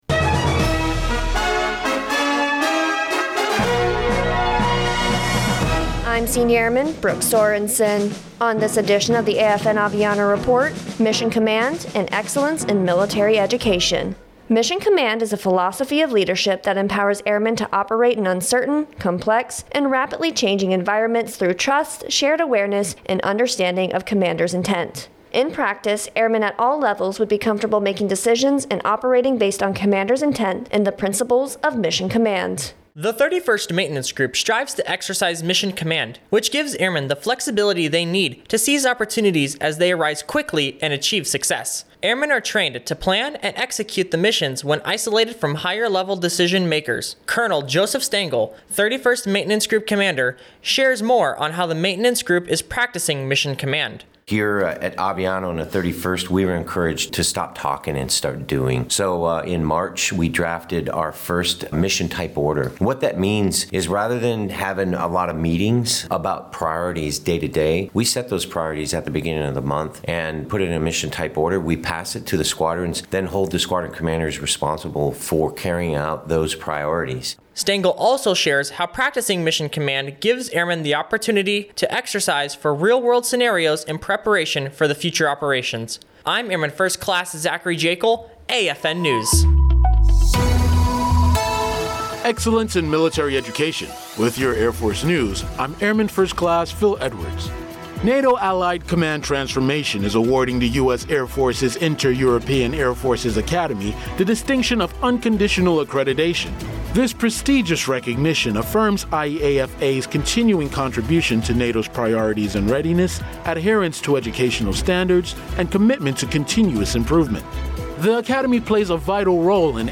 American Forces Network (AFN) Aviano radio news reports on how the 31st Maintenance Group is implementing mission command at Aviano Air Base, Italy. Mission Command is a philosophy of leadership that empowers Airmen to operate in uncertain, complex and rapidly changing environments through trust, shared awareness and understanding of commander’s intent.